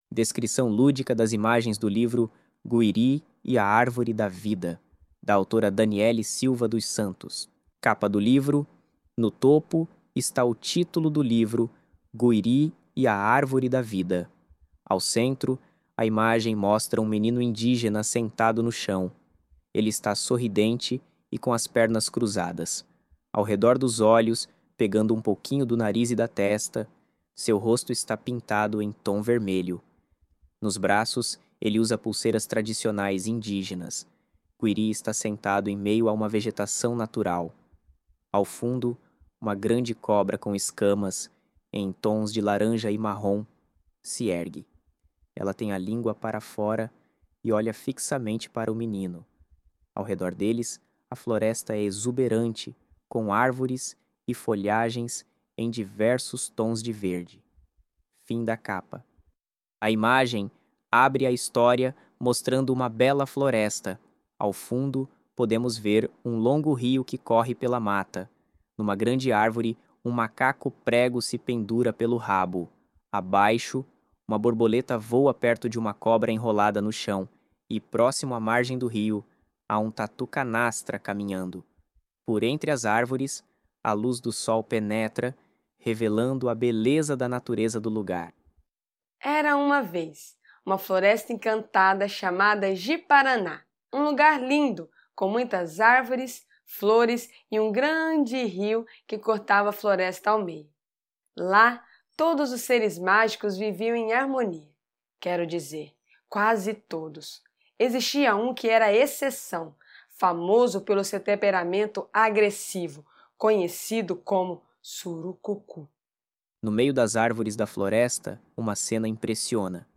guirii-e-a-arvore-da-vida-audiodescricao.mp3